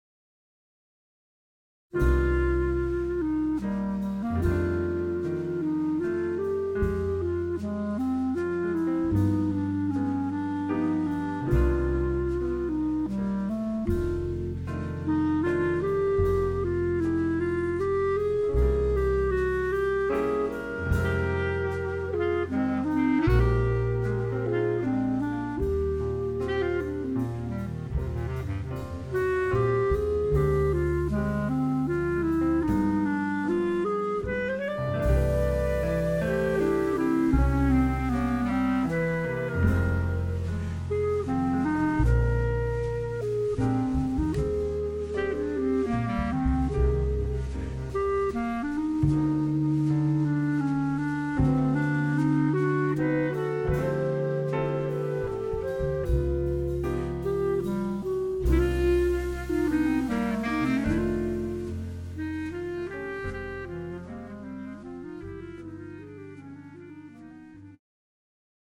The Best In British Jazz